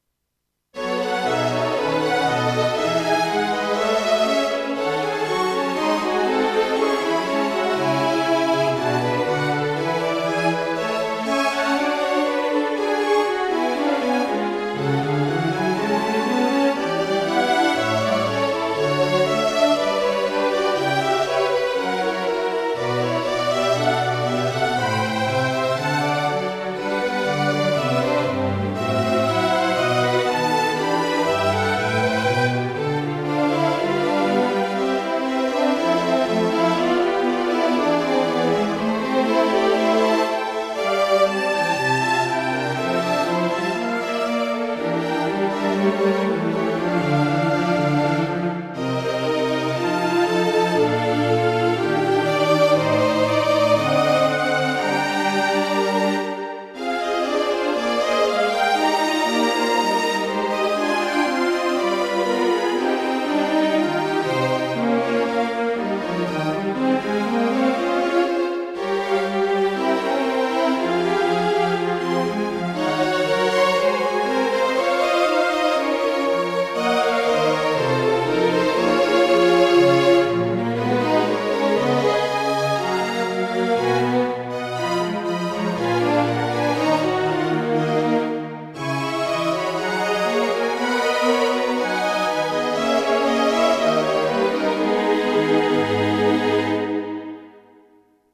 String Quartet
Written for String quartett.